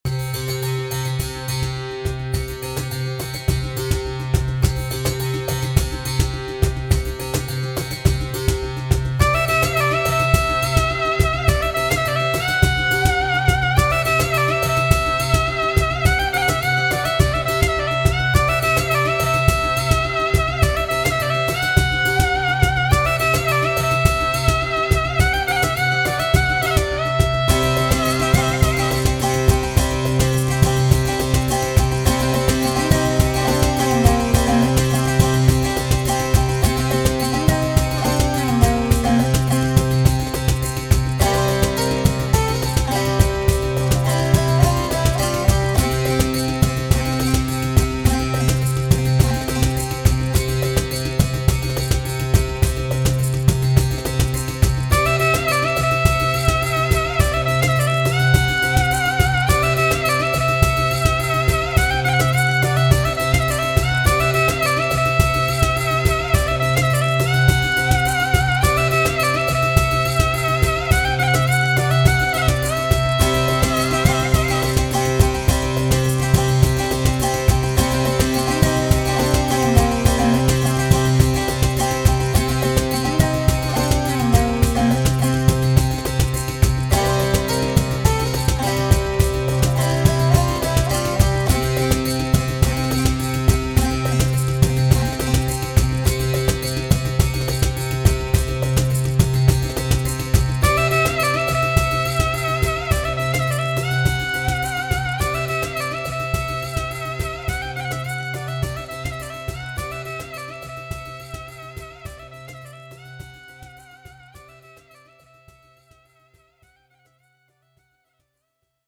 イタリア、ドイツ、ギリシャ、モロッコ、北アメリカあたりの民族音楽が混じっている。
タグ: ファンタジー 冒険 民族音楽 コメント: 架空の地域の市場をイメージした民族音楽風のBGM。